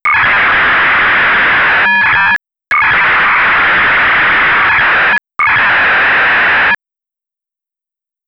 FLEXAFSK.wav